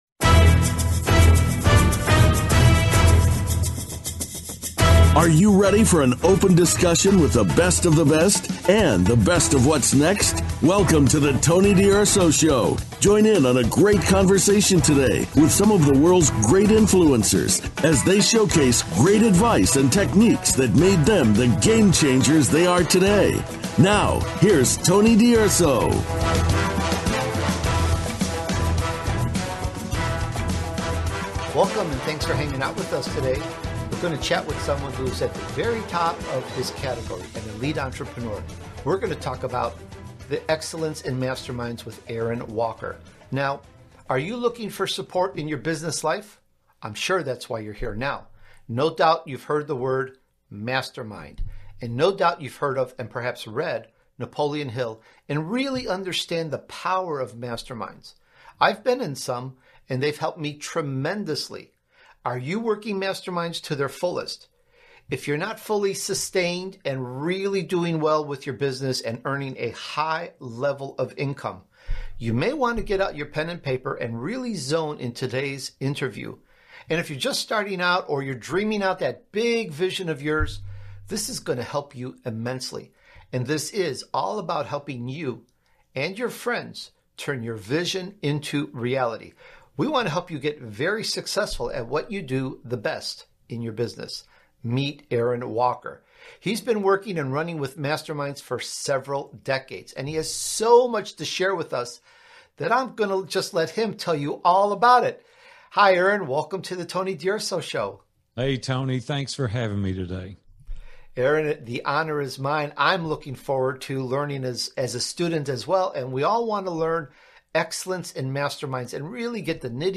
I interview some of the most successful people in the world, whom I call Elite Entrepreneurs.
Talk Show